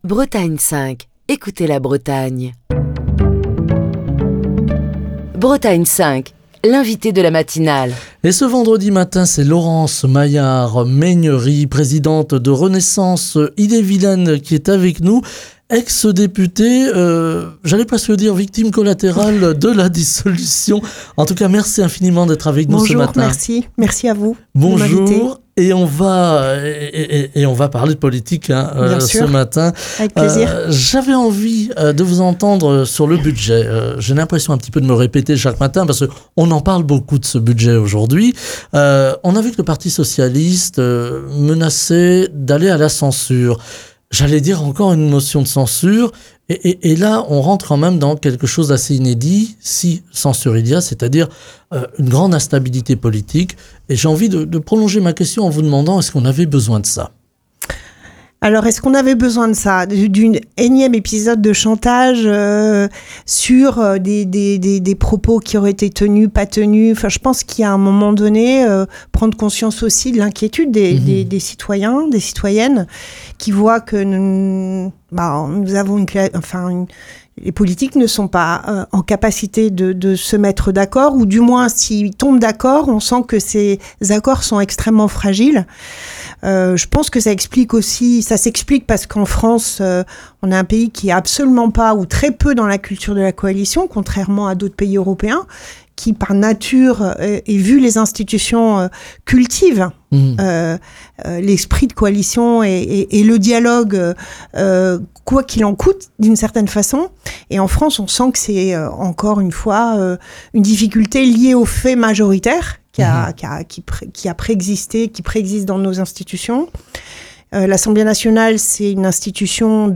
Ce vendredi, Laurence Maillart-Méhaignerie, présidente de Renaissance Ille-et-Vilaine, était l'invitée politique de la matinale de Bretagne 5. Laurence Maillart-Méhaignerie a abordé le risque d'une nouvelle motion de censure pesant sur le gouvernement Bayrou, motion que le Parti socialiste pourrait soutenir à la suite des récentes déclarations du Premier ministre.